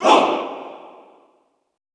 MALE HOO  -L.wav